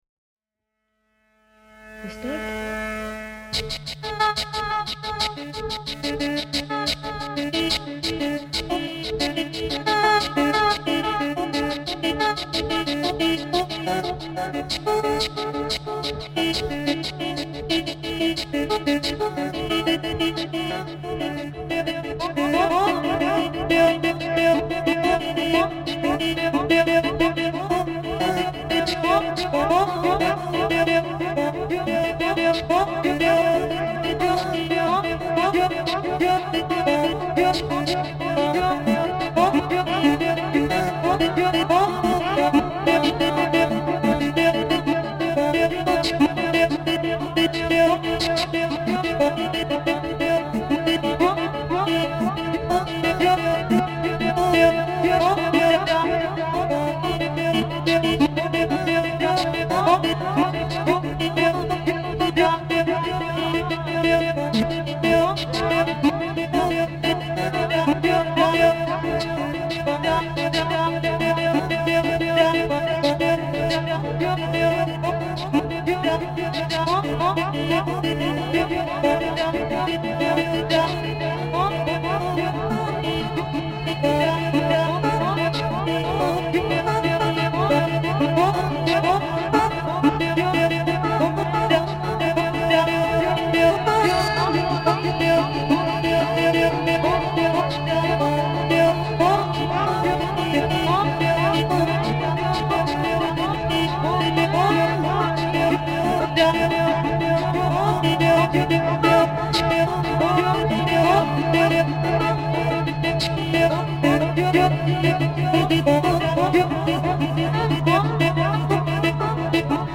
Her voice was so strong, so effortless, that I couldn’t hope to match it; I couldn’t hold the notes or even understand what she was saying.
a woman singing with harmonium